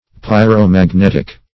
Search Result for " pyromagnetic" : The Collaborative International Dictionary of English v.0.48: Pyromagnetic \Pyr`o*mag*net"ic\, a. [Pyro- + magnetic.]
pyromagnetic.mp3